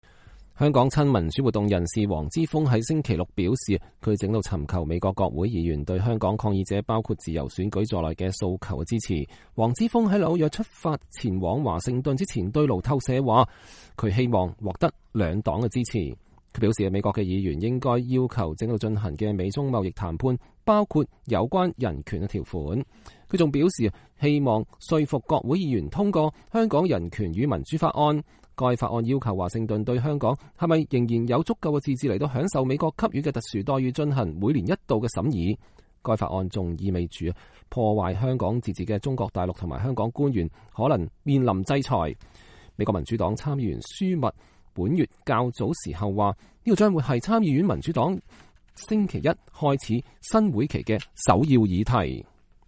黃之鋒2019年9月9號在香港東區法院門口見媒體。（美國之音）